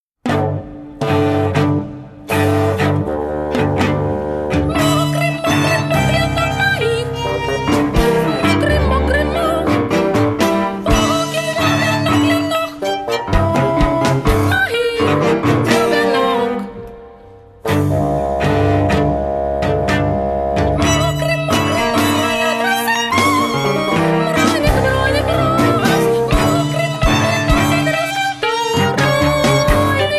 chamber music, rock, theatre and improvisation
Violin, cello, bassoon, saxes and accordion